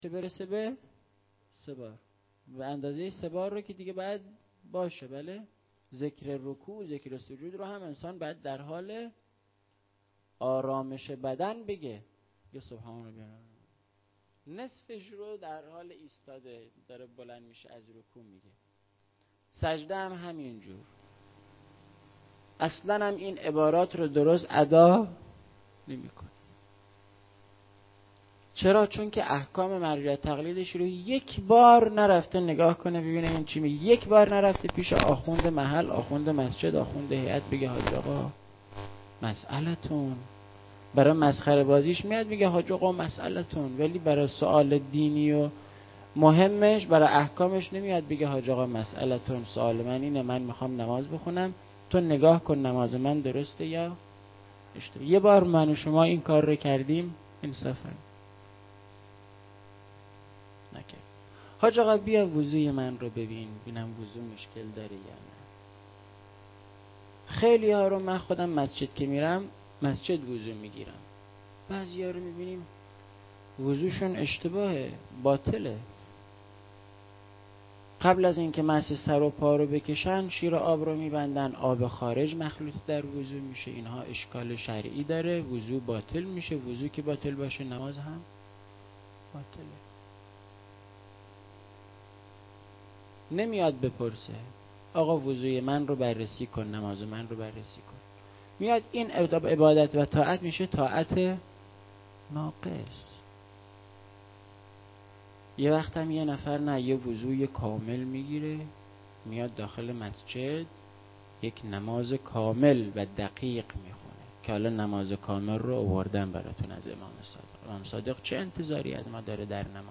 سخنرانی
مراسم هفتگی 29 محرم سال 94.mp3